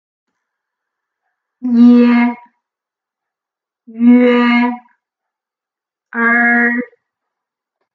Uitspraak van klinkers:
ie ue er (audio)
10-ie-ue-er-_audio.mp3